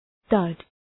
Shkrimi fonetik {dʌd}